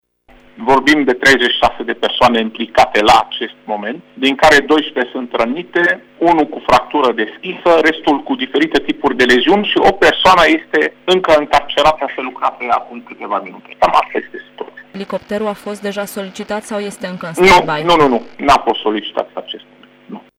Șeful Departamentului de Urgență din cadrul MAI, Raed Arafat, a declarat pentru Radio Tg. Mureș că din informațiile primite de la fața locului, leziunile suferite de cele 12 persoane sunt medii și ușoare, iar până în acest moment elicopterul SMURD Tîrgu Mureș nu a fost solicitat: